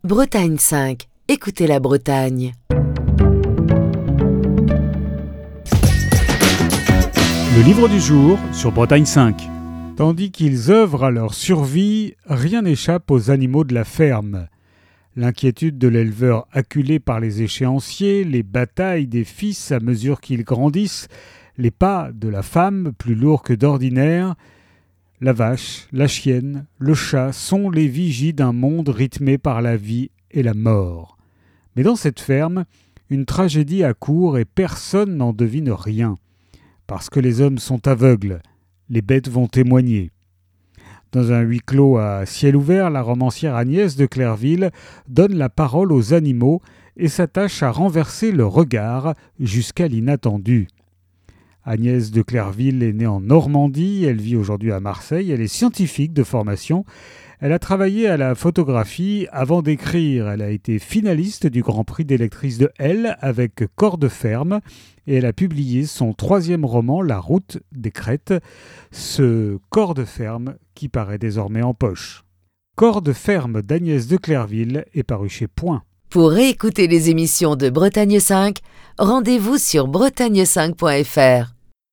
Chronique du 25 juin 2025.